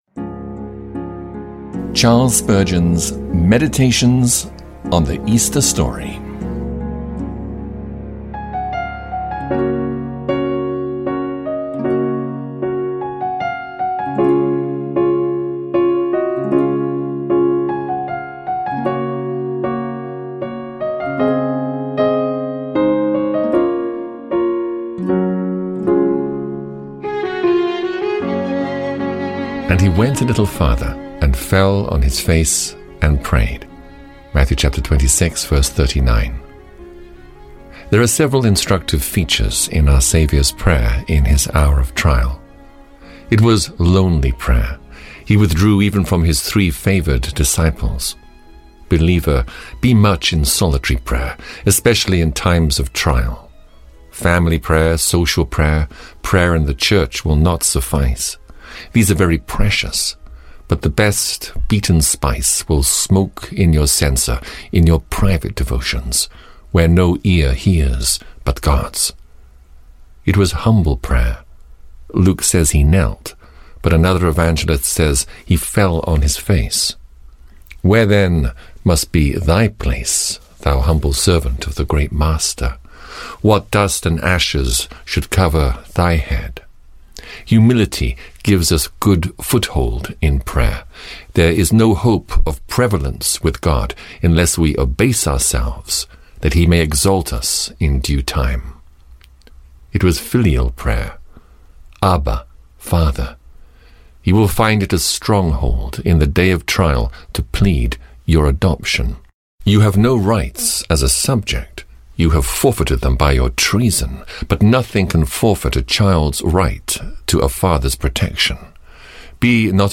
Complimented with short instrumental interludes composed by Michael Dooley.